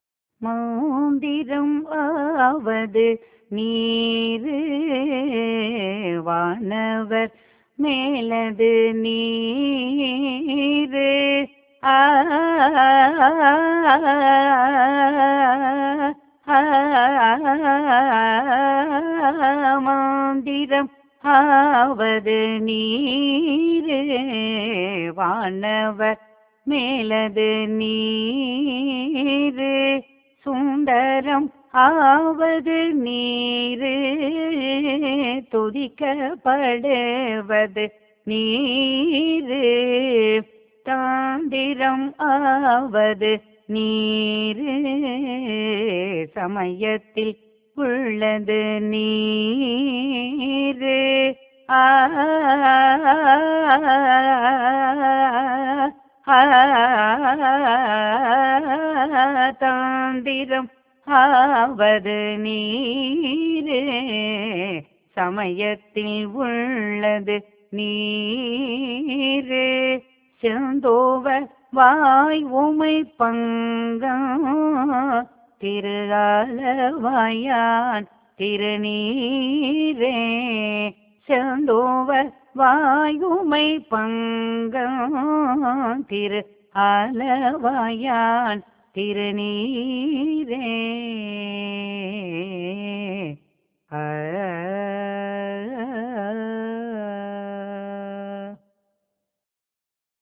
பண்: காந்தாரம்